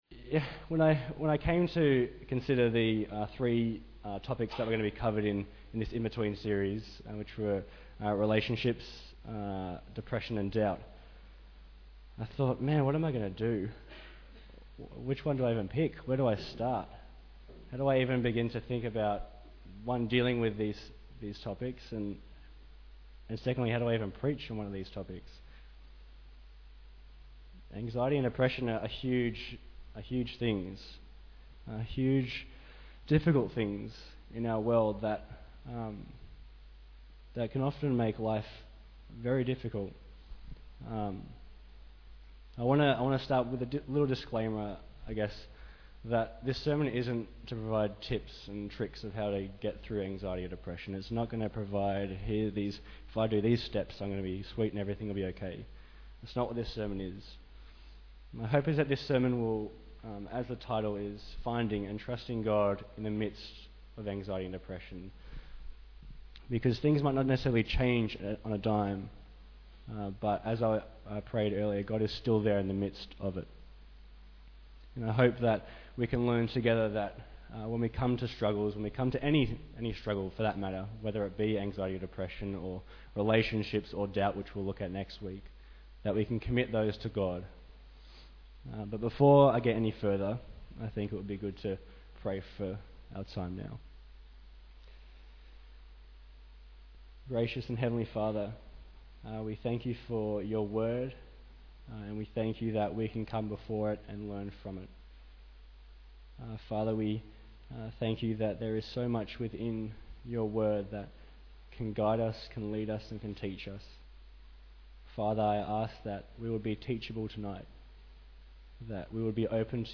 Bible Text: 1 Peter 5:6-7 | Preacher